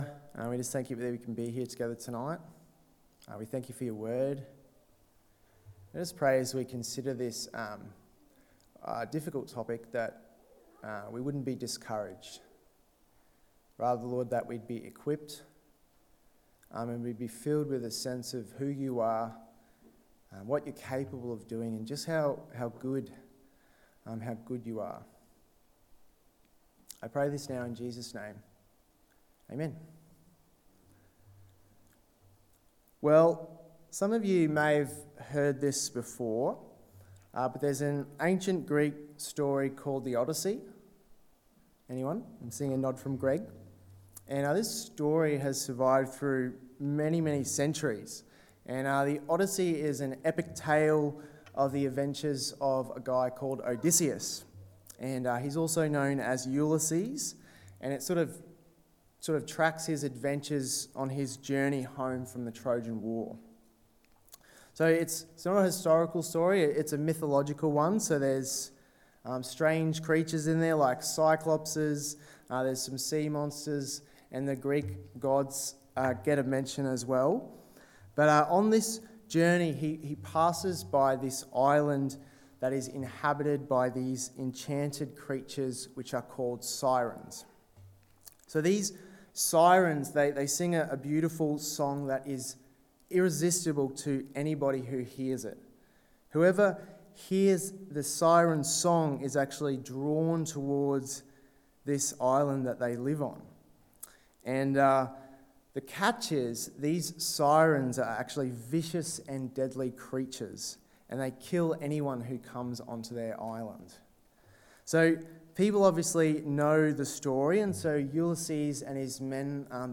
Standing Strong against Temptation (James 1:12-18 Sermon) 28/05/23 Evening Service